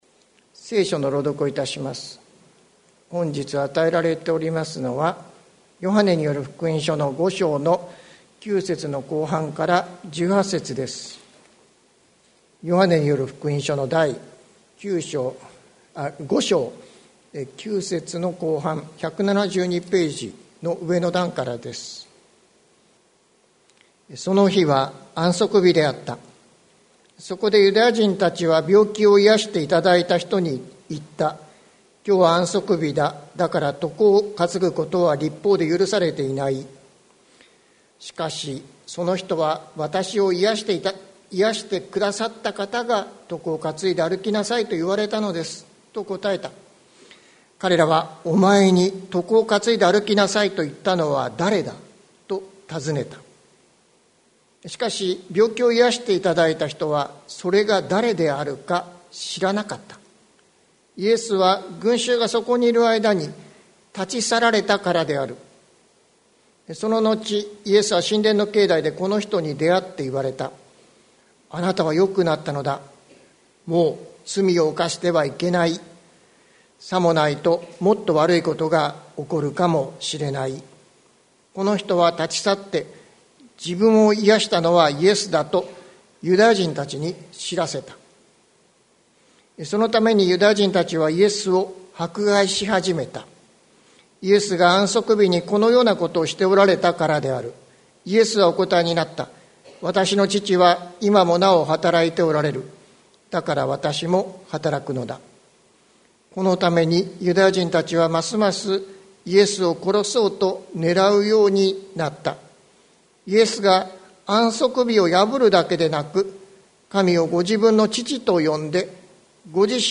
2022年03月13日朝の礼拝「あなたの告白」関キリスト教会
説教アーカイブ。